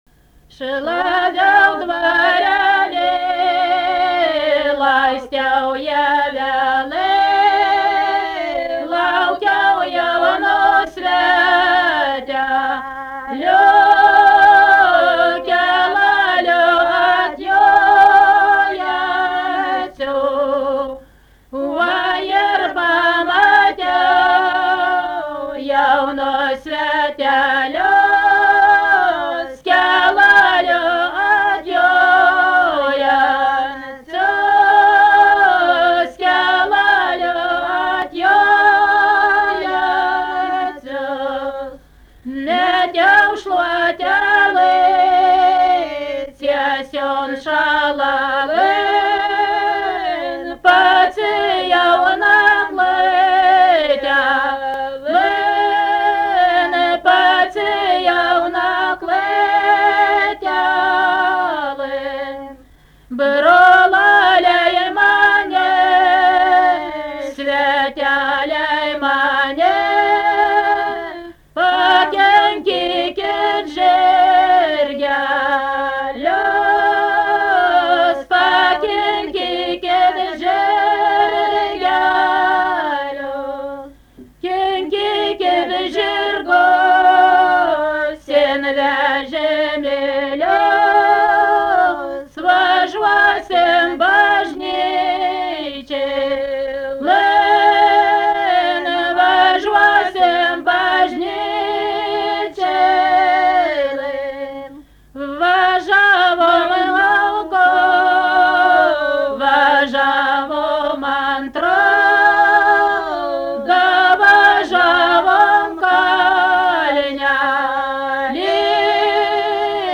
daina
Panočiai
vokalinis
2 balsai; Nur. kaimas Slučajus, nebėra (DA)